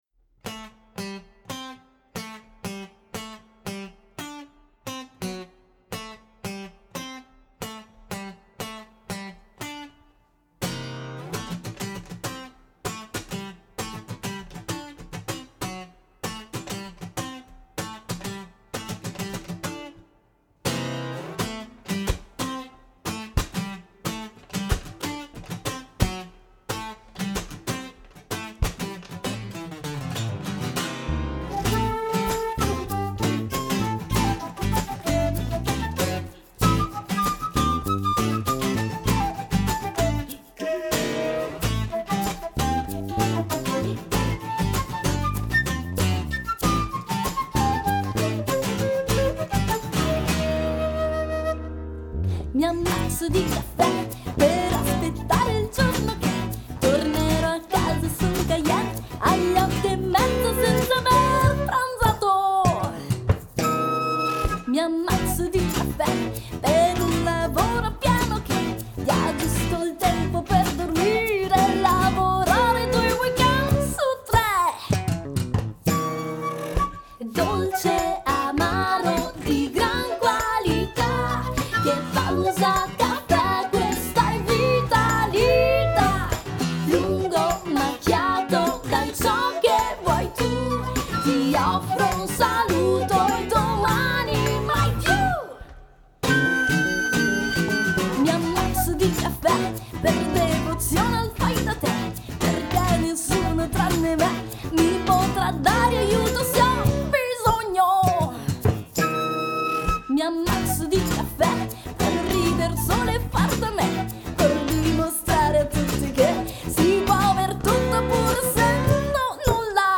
flauto traverso
percussioni